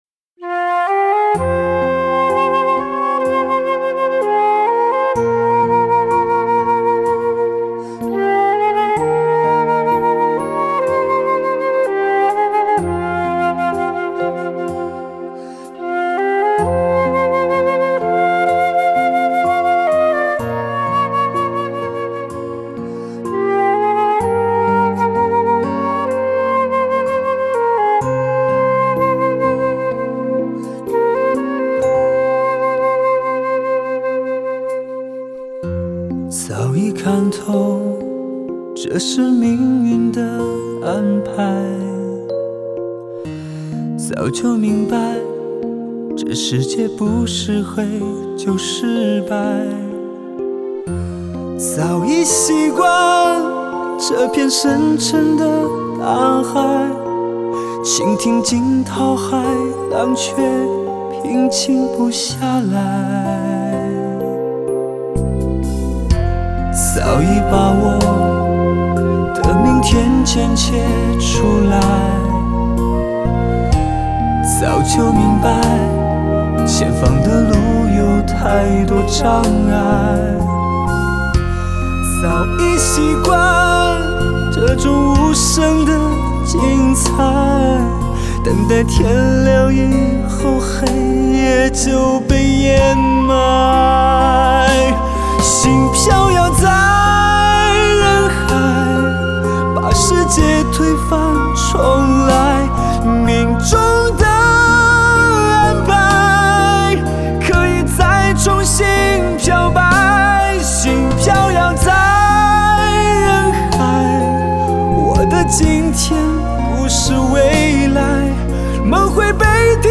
一个有着魔力的天籁男声，有着音乐和心灵相通的灵魂